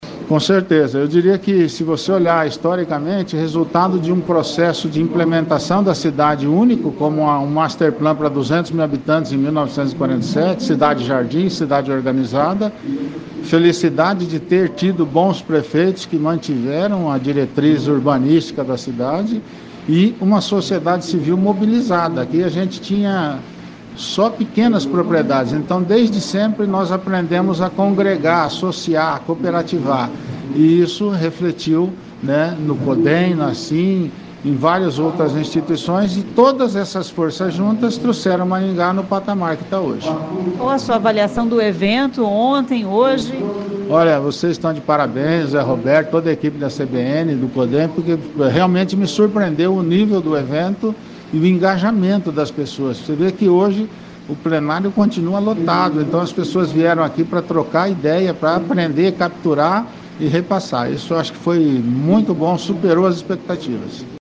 A entrevista foi realizada no estúdio móvel CBN instalado no local do evento.